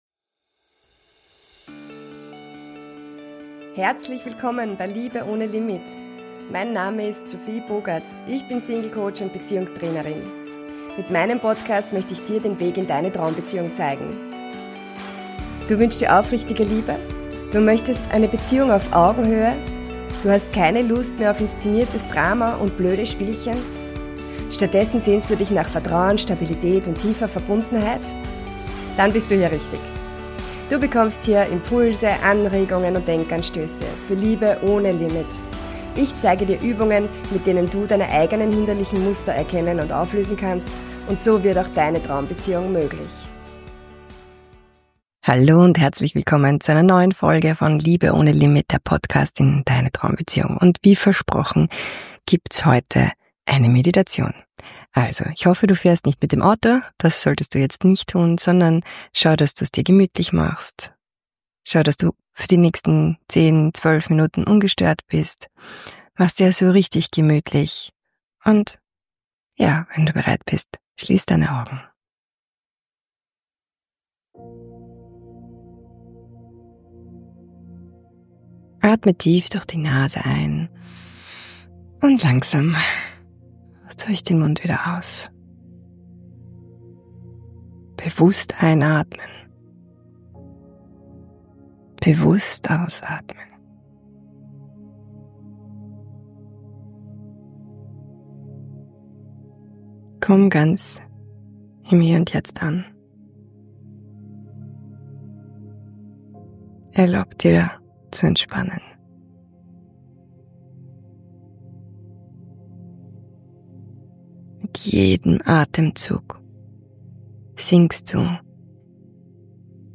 Mit heilsamen Bildern – wie einer Hängematte aus Licht, die dich trägt, und Schmetterlingen, die deine Sorgen davontragen – lernst du, belastende Gedanken und Gefühle liebevoll loszulassen. Diese Meditation hilft dir, dich in dir selbst geborgen zu fühlen, alte Verletzungen zu heilen und dem Leben wieder zu vertrauen.